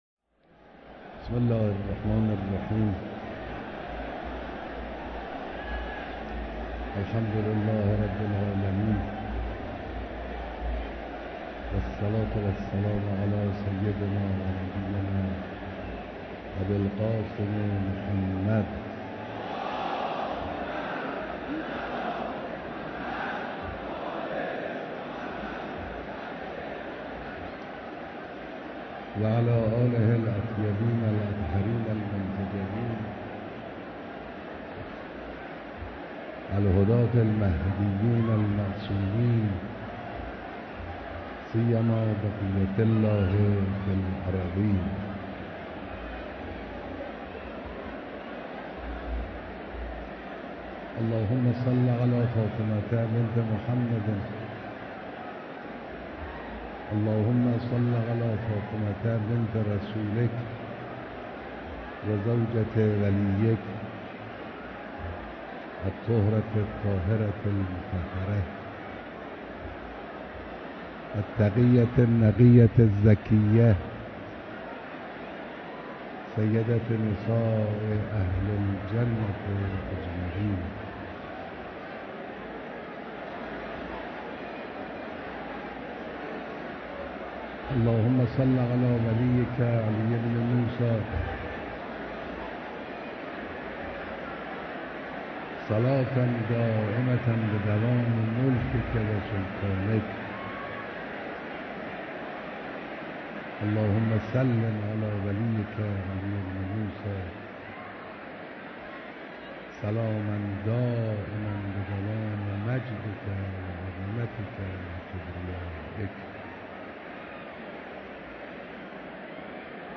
بیانات در اجتماع عظیم زائران و مجاوران حرم رضوی علیه‌السلام